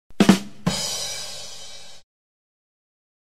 badumtiss.mp3